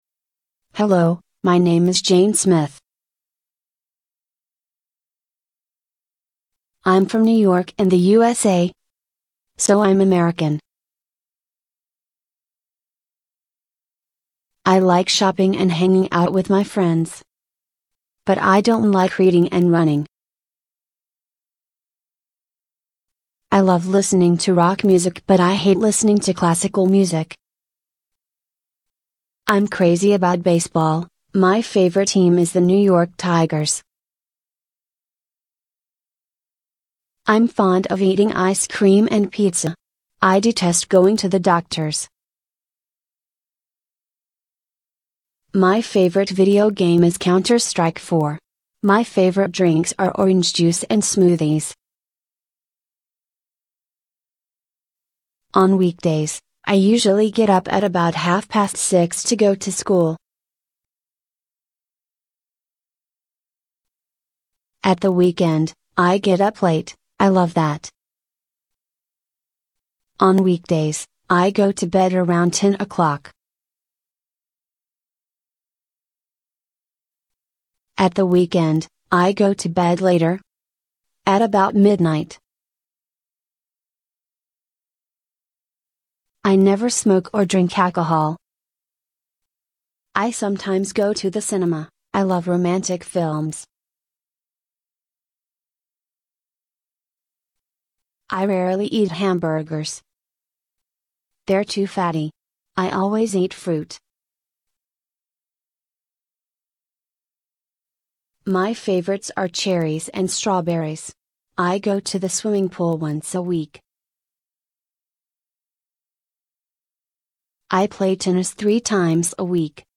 Listen to the boy speaking and t